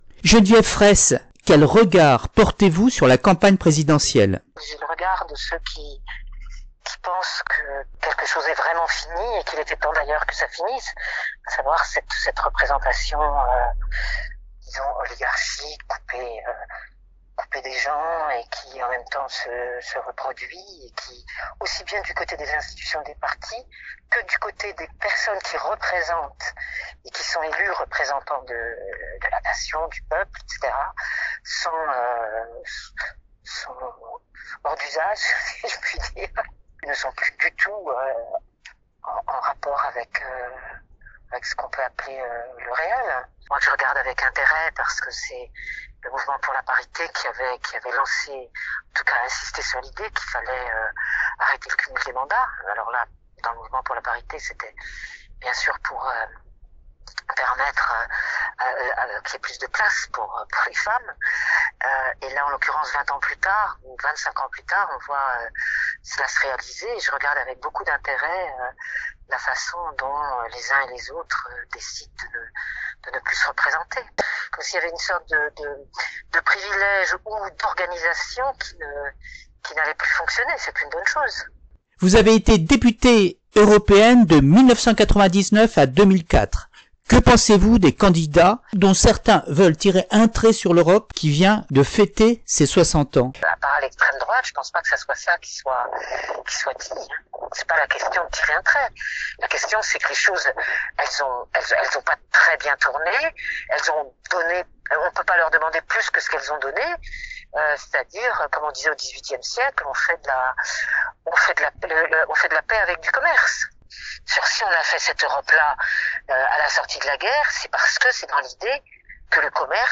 Après les propos recueillis sur le marché de Cabourg, j’ai réalise plusieurs interviews pour GLOBAL Magazine. Geneviève Fraisse, philosophe et historienne féministe, s’inquiète d’un fonctionnement de la République et de l’Europe qui ne convient plus.